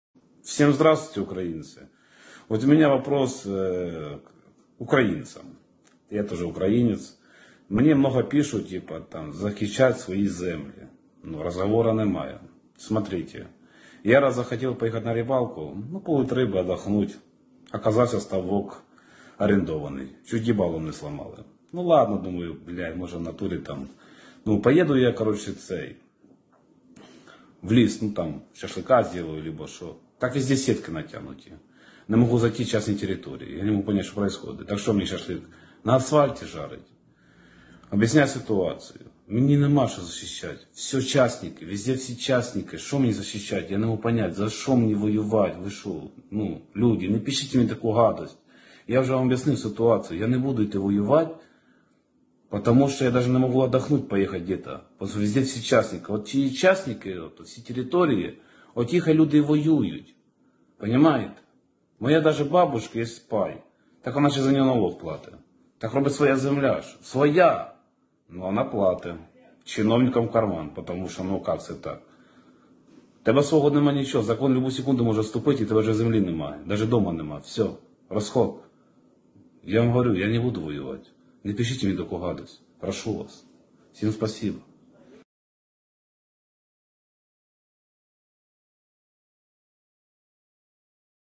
Ein populärer ukrainischer Tiktoker erklärt die Situation im Land und warum er nicht an die Front gehen wird, um seine Heimat zu verteidigen. Er stellt dabei die Klassenfrage, die auf die grosse Mehrheit ebenso zutrifft: Warum für die Eigentümer des Landes sein Leben riskieren?